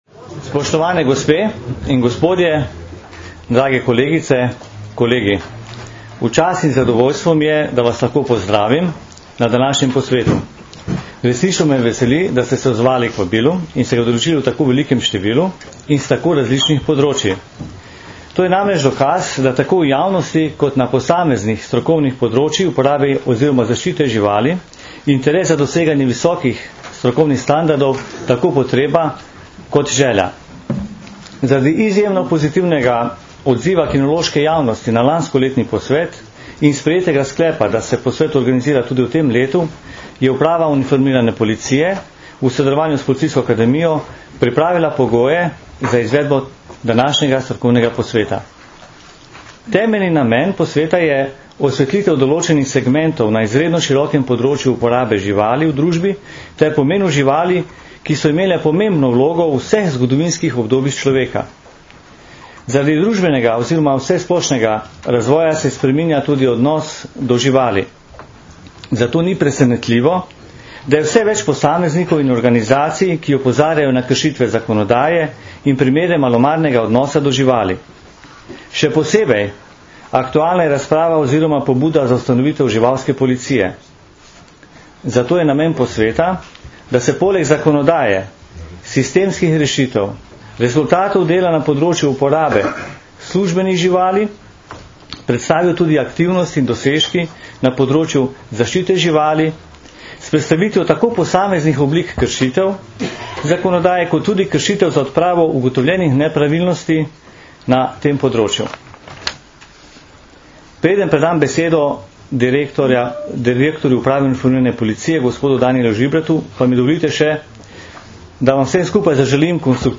V Policijski akademiji v Tacnu smo danes, 11. novembra 2010, organizirali že drugi strokovni posvet s področja uporabe živali in njihove zaščite.
Zvočni posnetek govora